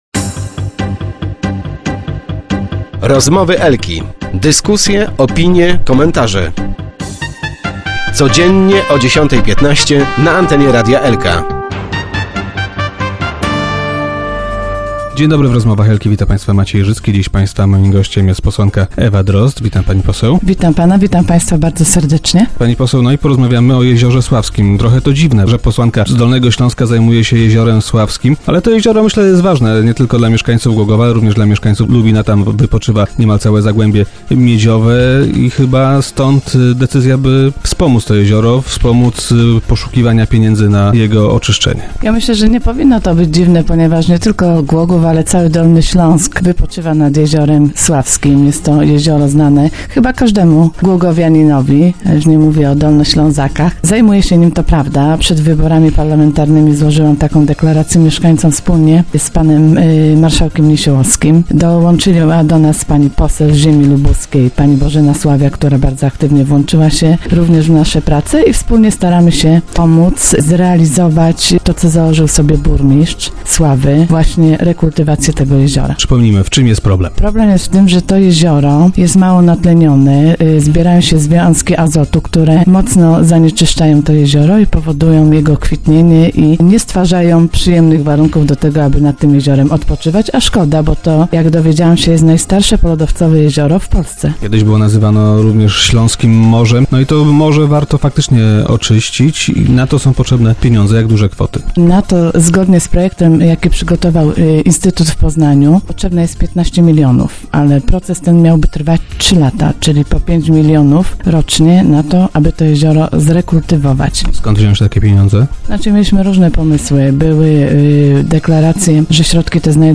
- Obiecałam to w swojej kampanii wyborczej - powiedziała Ewa Drozd, która była dziś gościem Rozmów Elki.